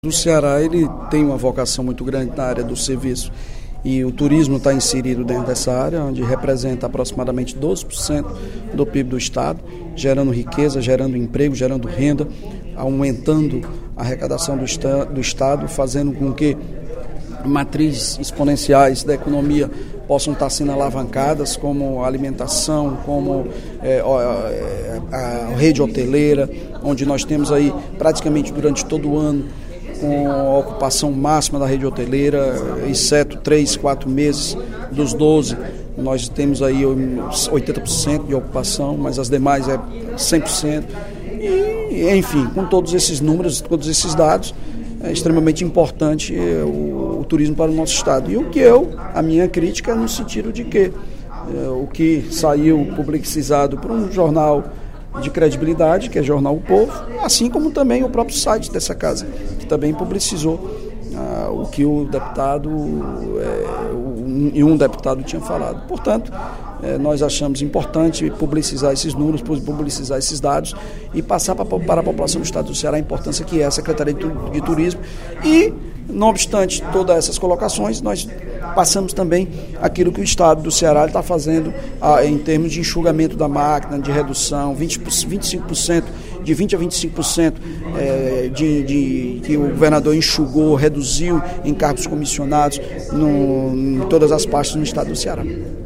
O líder do Governo na Casa, deputado Evandro Leitão (PDT), destacou, no primeiro expediente da sessão plenária desta terça-feira (14/06), o papel da Secretaria de Turismo do Estado no desenvolvimento do Ceará e criticou a extinção da pasta, que teria sido defendida por um parlamentar.